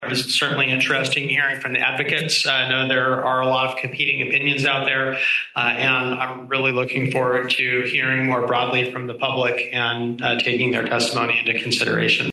County Commissioner Mark Ozias says commissioners have heard from a band of supporters, but he wants the rest of the public to weigh in.